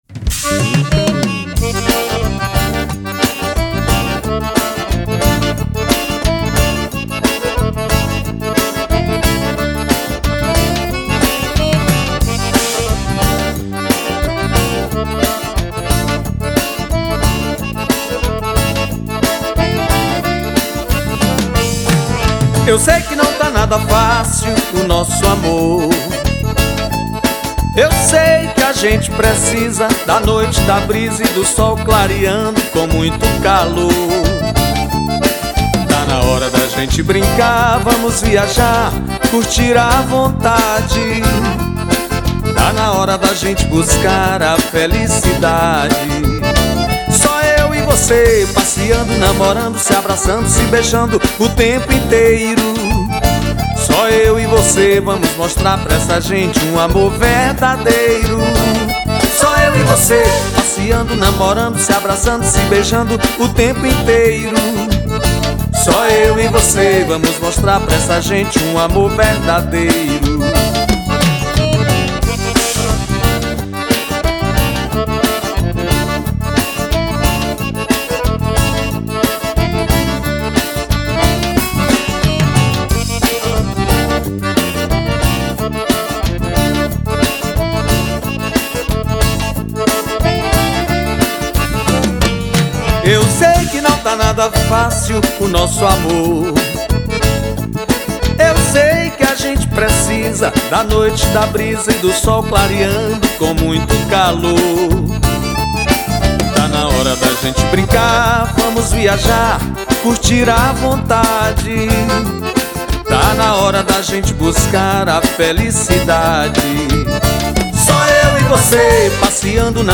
2531   03:43:00   Faixa: 10    Baião